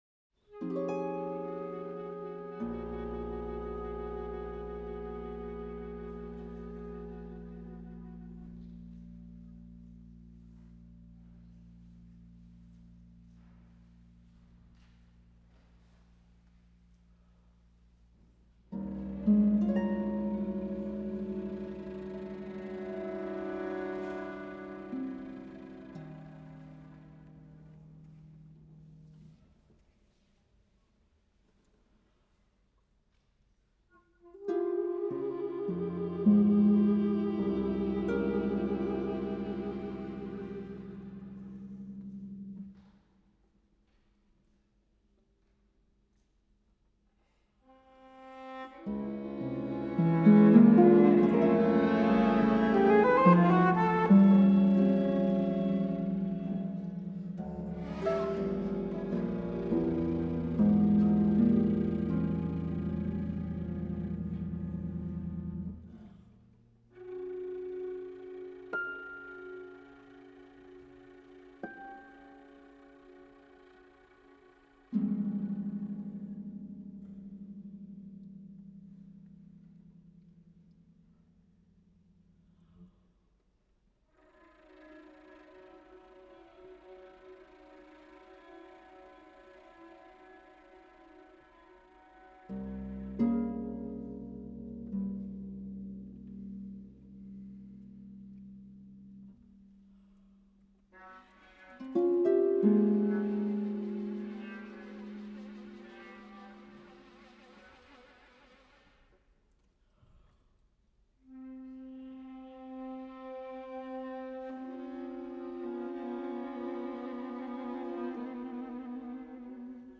für Flöte, Viola und Harfe